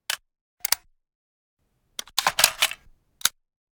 Added UAV exchange battery sound
exchange_battery.ogg